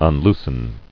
[un·loos·en]